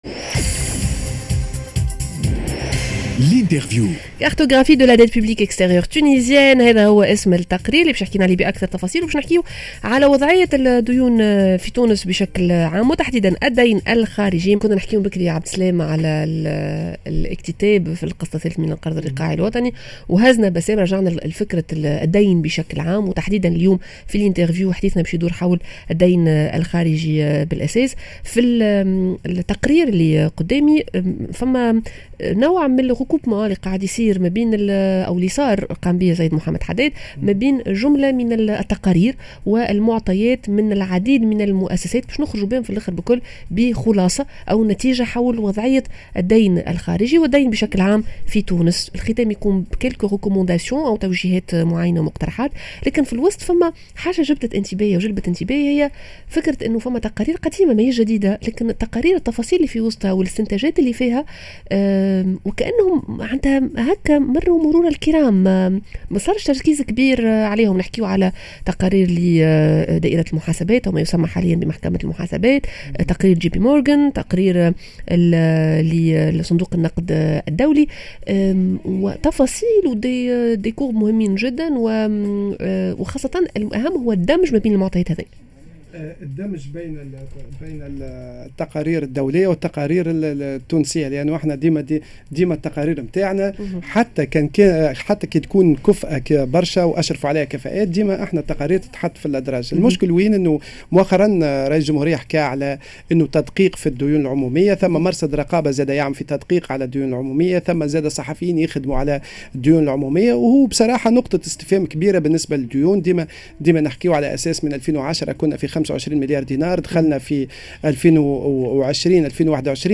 تقرير حول الدين الخارجي لتونس